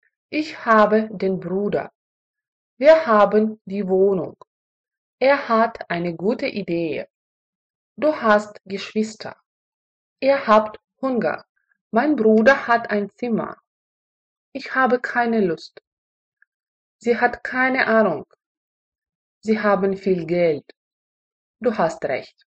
Аудиокурс немецкий язык за 7 уроков — урок 14